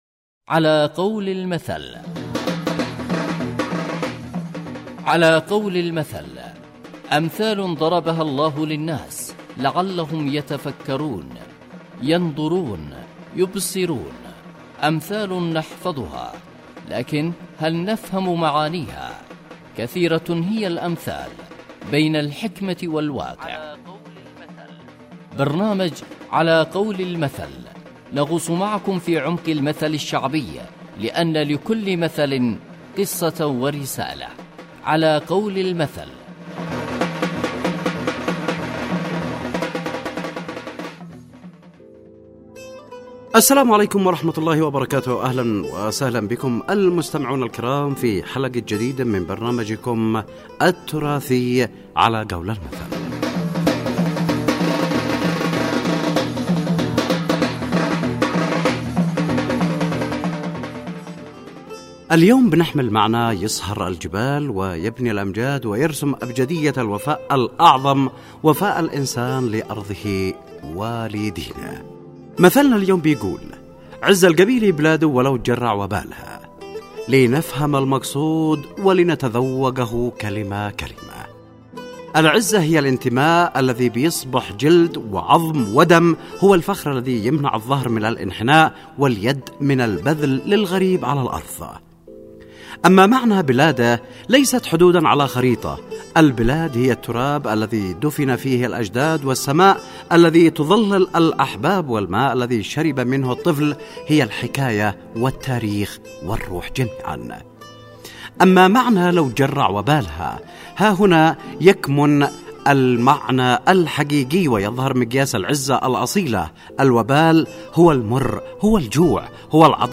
برنامج إذاعي يحكي عن معاني الامثال والحكمة منها كالمثل العسكري أو المثل المرتبط بأية قرآنية او المثل الشعبي . ويقدم تفسير للمثل والظروف التي أحاطت بالمثل وواقع المثل في حياتنا اليوم ويستهدف المجتمع.